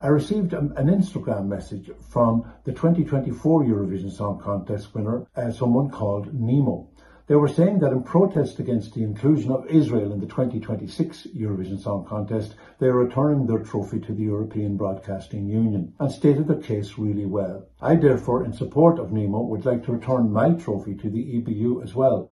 In a video posted online, Charlie McGettigan says he was prompted by a message from last year’s winner: